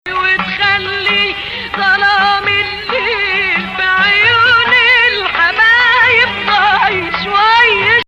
Hijaz 5